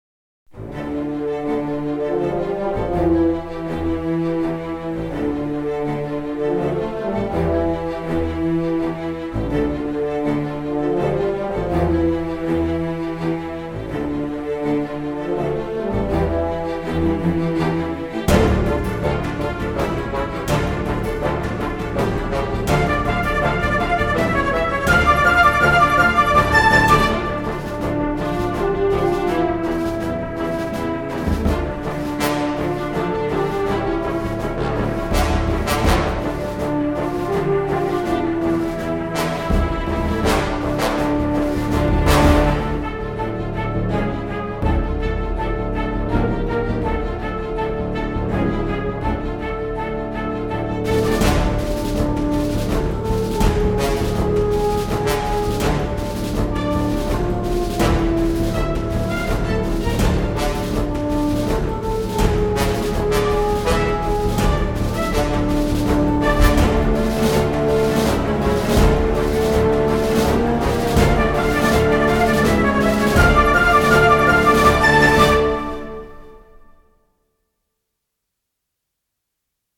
Orchestral track for strategy and RPG wargames.